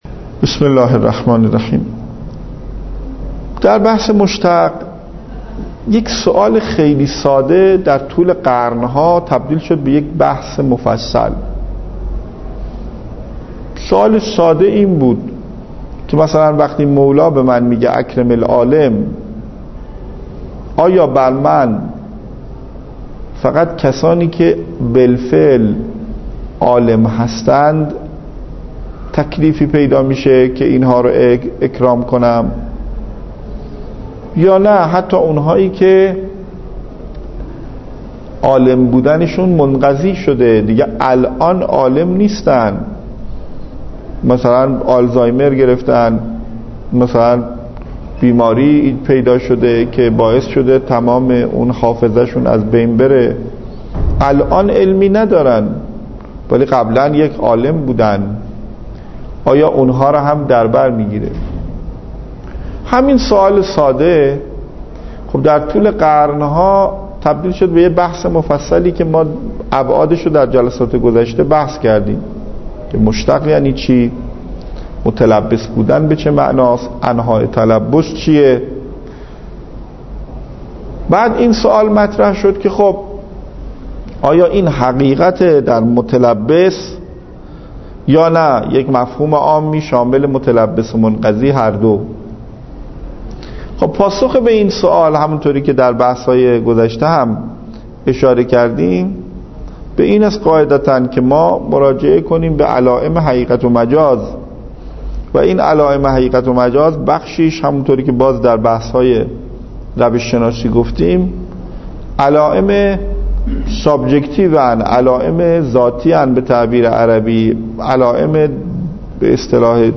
درس خارج اصول